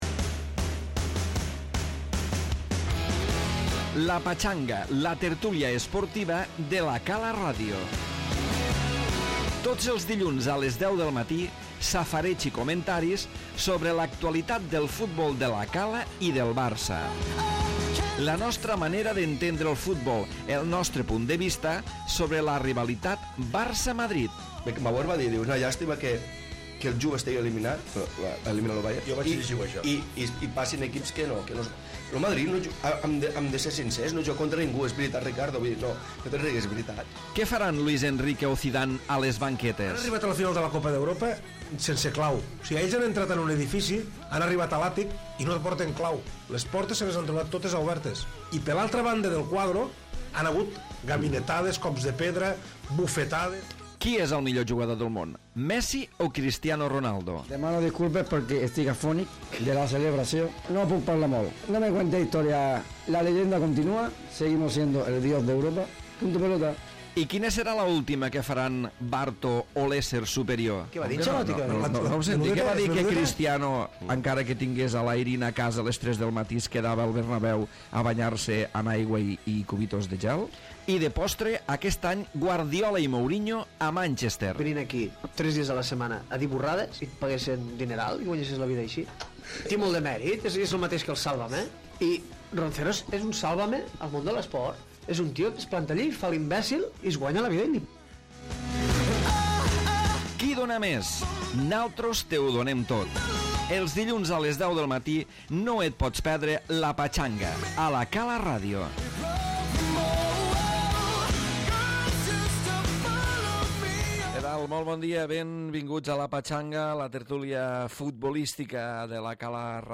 Tertúlia futbolística centrada en les possibilitats de remuntada del Barça davant del PSG a la Champions